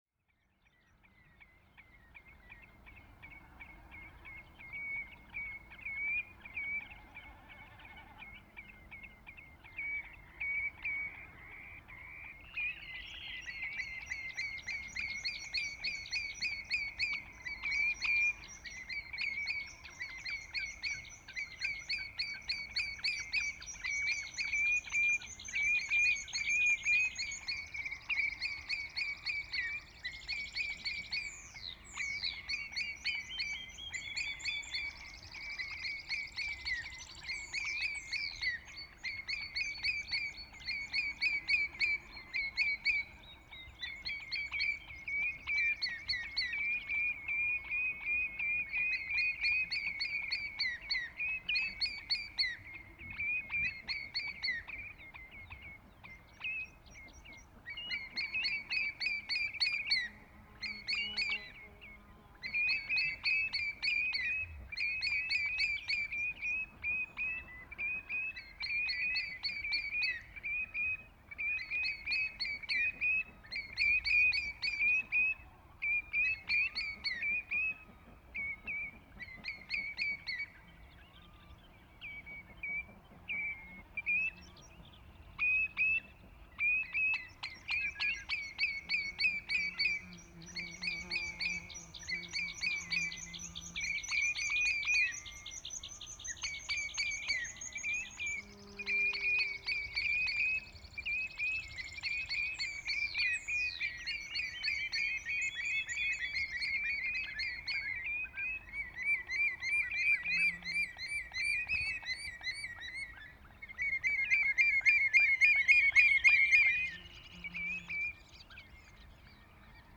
It is rear now a days to discover new places without traffic or engine nose.
It was at Fellströnd, in the northwest of Iceland.
No traffic, or engine noise, means more natural silence and more transparent soundscape.
It is even possible to hear sheep footsteps far away and detect what bird species are in the area, even far away.
This is a high gain recording, recorded at +50dB. Gain was then increased in post about +25dB, normalized at -8dB and gently NR. This is a part of 10 hour recording. This part was recorded between 7 and 8 in the morning at 21st of June. Many bird species are audible in this recording.